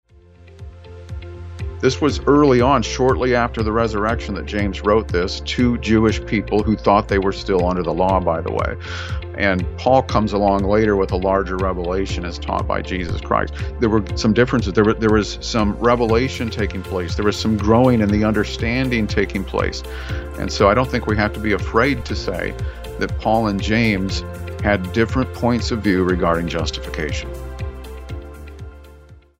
As our series continues regarding the apparent differences between Paul and James on the subject of justification, this week's program results in a spontaneous conversation which discusses what it means if they did actually view some things in an opposing fashion. As we've personally concluded in recent weeks, Paul and James were not in perfect harmony with their statements and we review some possible reasons why this could have been the case.